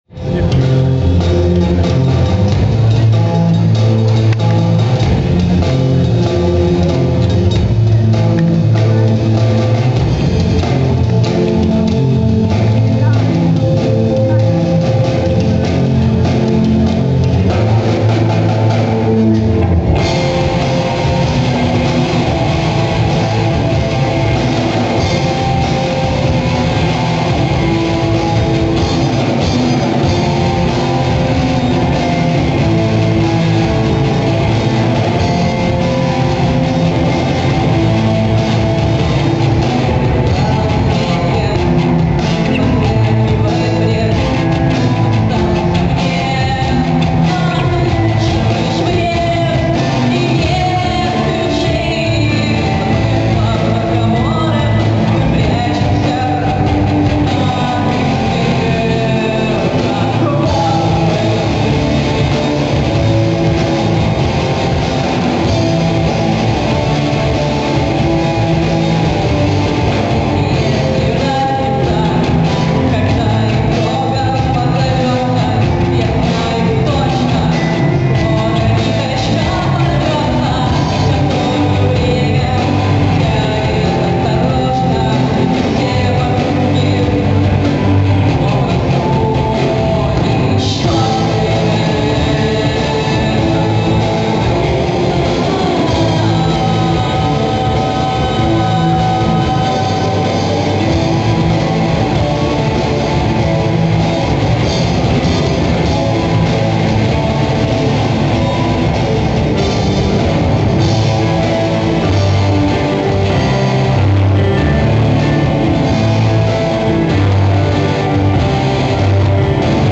гитара, вокал
барабаны, вокал
бас-гитара
клавиши, вокал
(live)